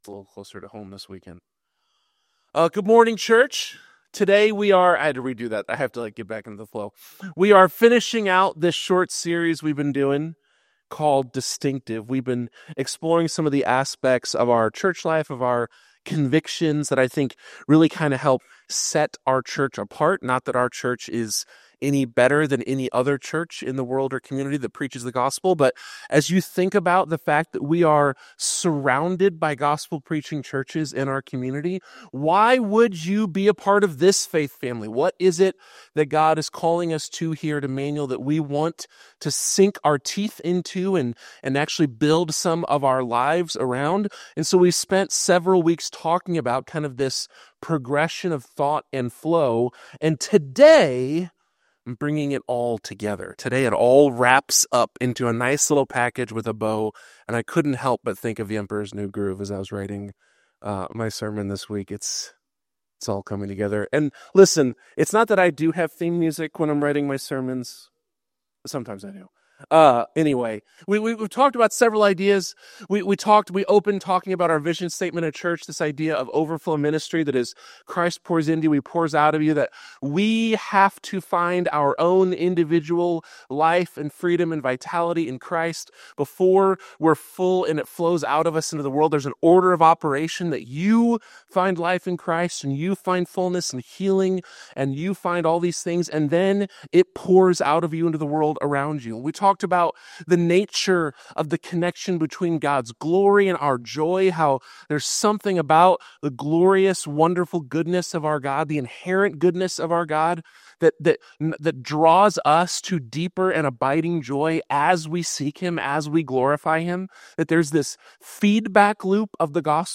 Whether you're new to faith or a seasoned believer, this message challenges us to live distinctively for the kingdom of God.